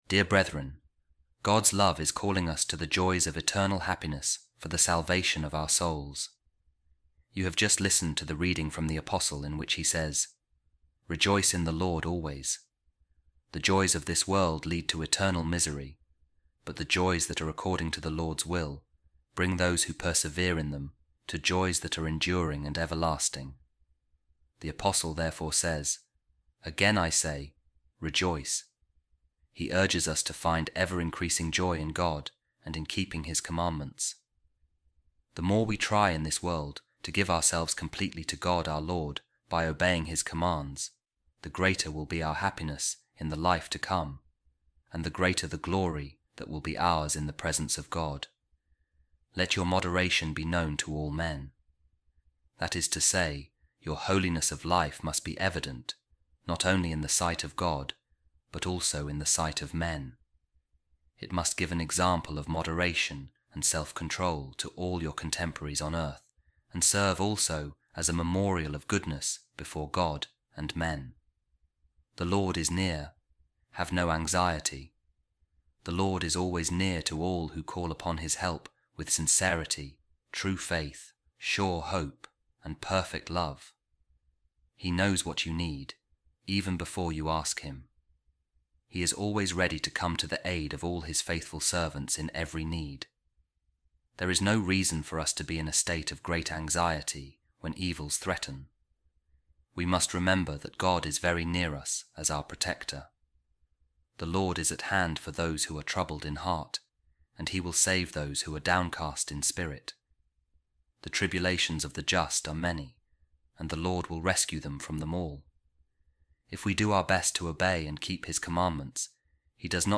A Reading From The Commentary Of Saint Ambrose On The Letter To The Philippians | Be Joyful In The Lord Always